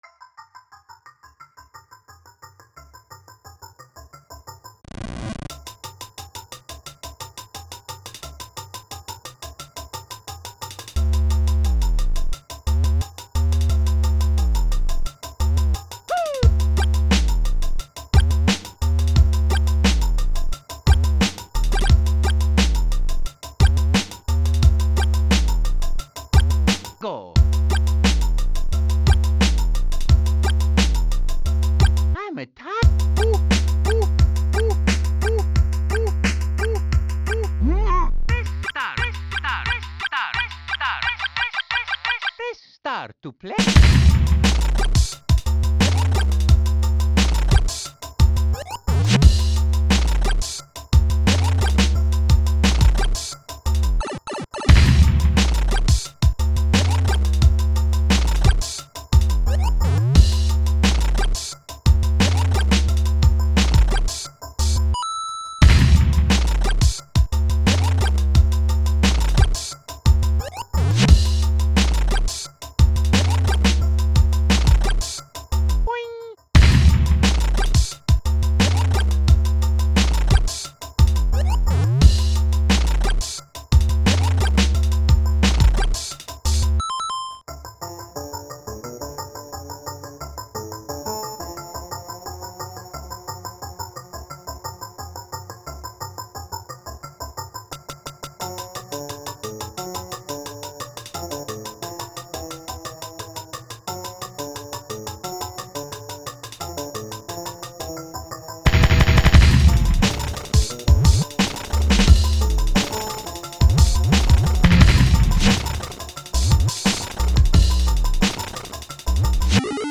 Music / Game Music